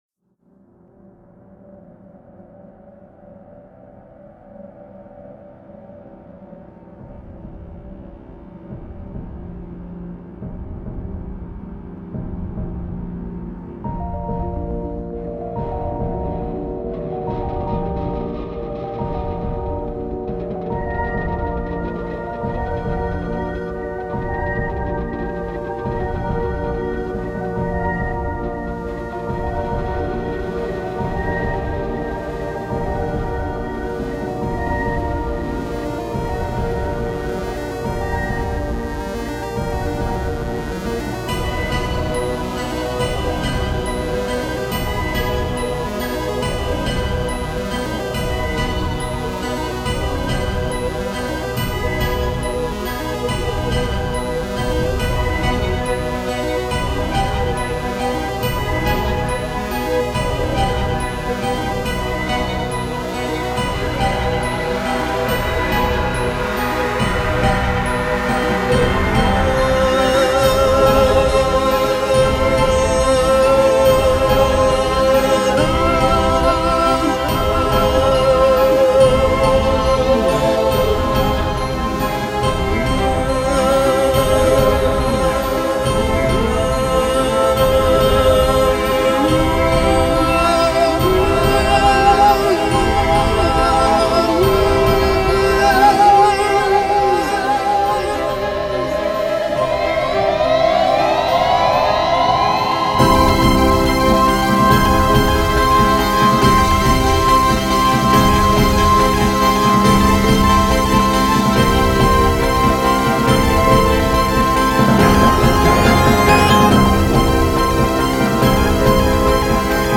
cantante principal y guitarra eléctrica.
voces y bajo eléctrico
synth, piano.
drum and electric drum.
Rock Alternativo